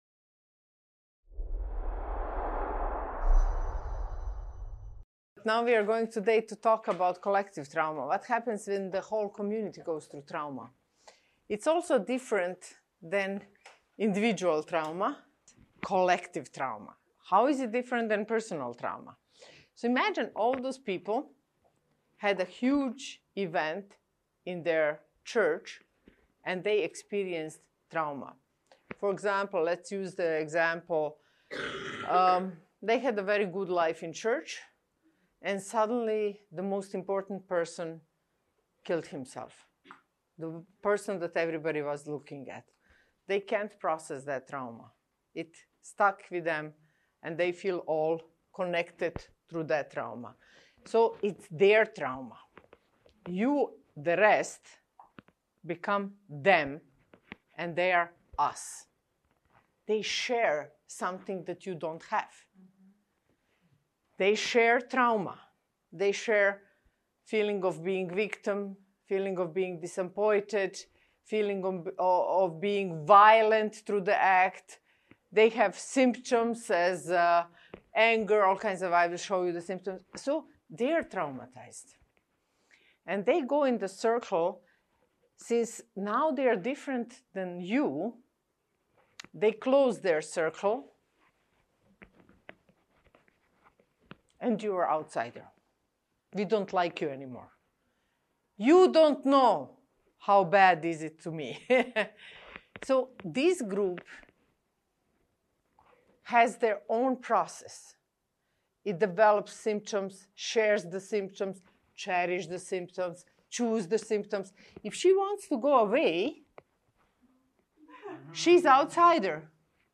How do things like wars and natural catastrophes impact a whole community? In this talk, we explore what collective trauma is and how understanding it can help us support people in breaking the circle of violence and loyalty to a destructive community.